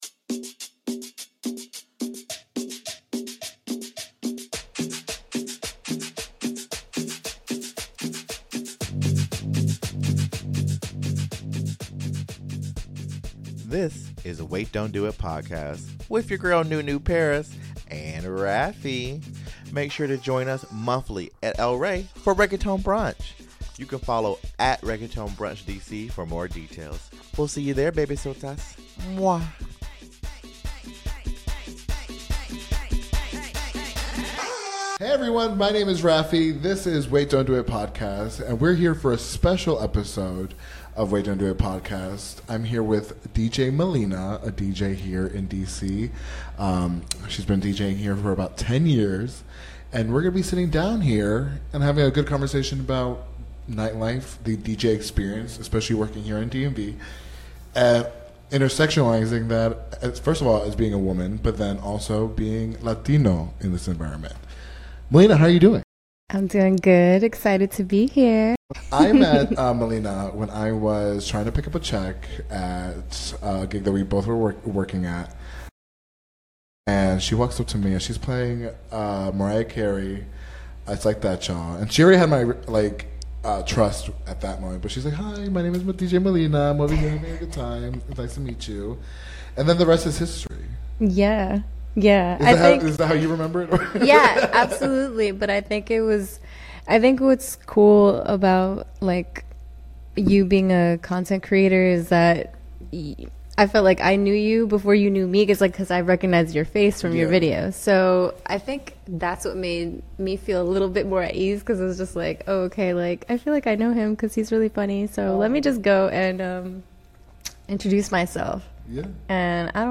Be a guest on this podcast Language: en Genres: Comedy , Education , Self-Improvement Contact email: Get it Feed URL: Get it iTunes ID: Get it Get all podcast data Listen Now... Wait! Don't Kill The Vibe! (Interview